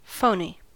Ääntäminen
IPA : /fəʊni/